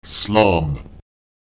Lautsprecher slám [slaùm] sich fortbewegen